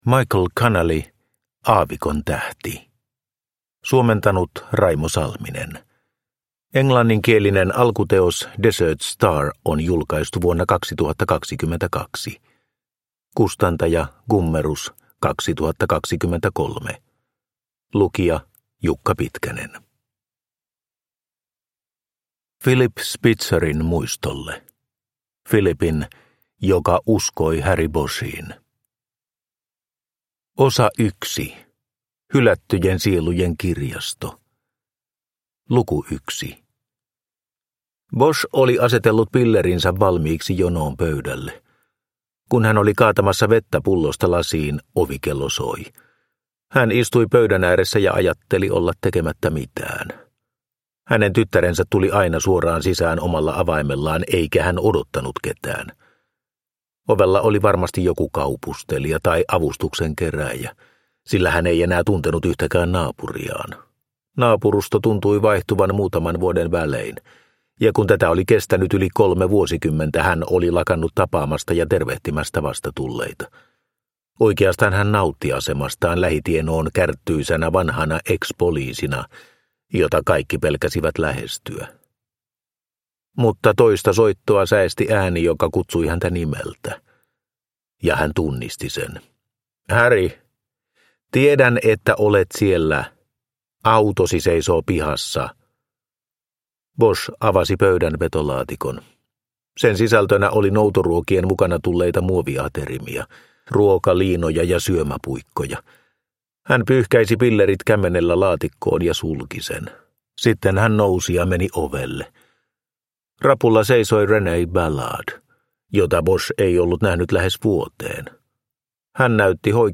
Aavikon tähti – Ljudbok – Laddas ner